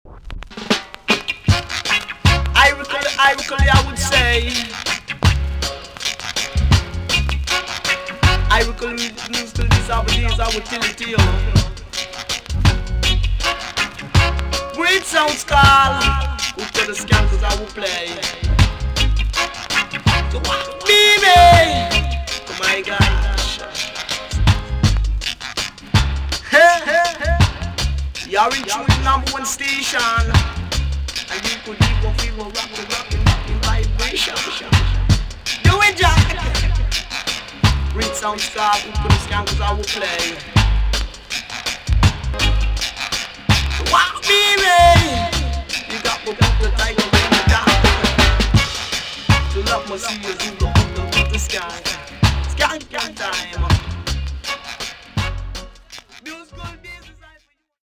TOP >REGGAE & ROOTS
VG+ 少し軽いチリノイズがありますが良好です。
1972 , NICE TOASTING STYLE!!